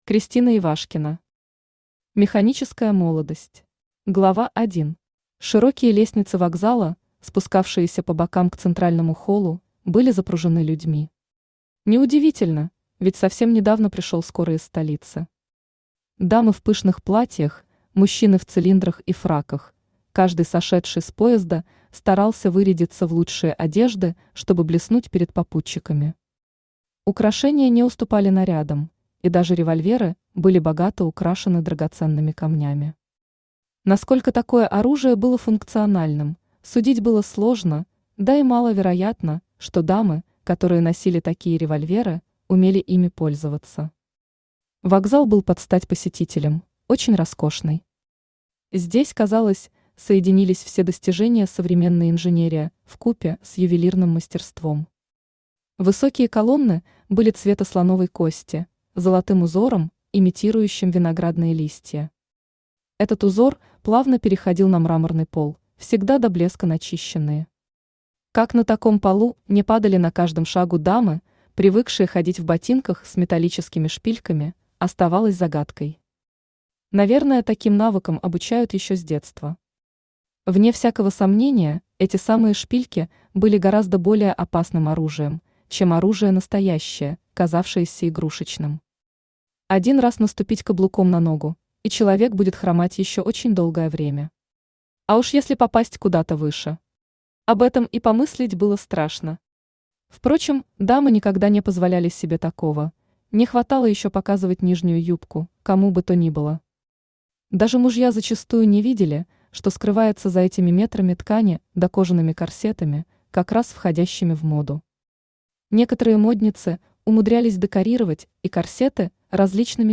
Аудиокнига Механическая молодость | Библиотека аудиокниг
Aудиокнига Механическая молодость Автор Кристина Николаевна Ивашкина Читает аудиокнигу Авточтец ЛитРес.